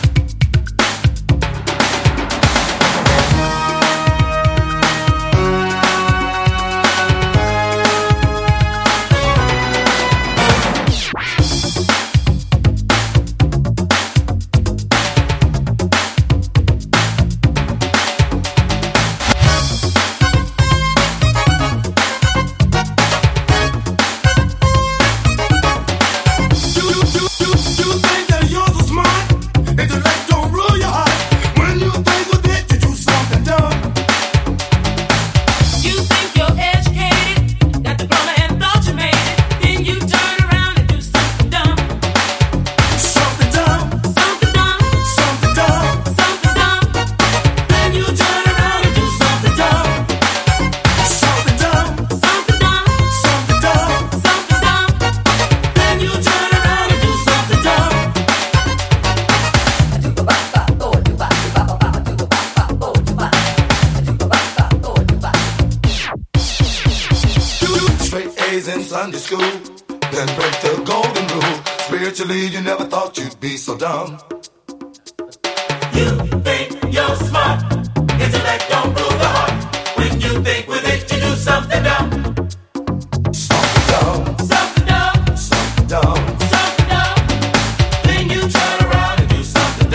EASY LISTENING / VOCAL / JIVE / OLDIES